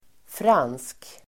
Uttal: [fran:sk]